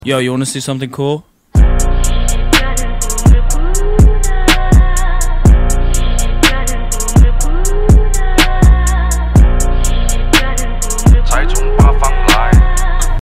Upgrading airflow on the FL5 Civic Type R. Turbo inlet + carbon intake = power and sound.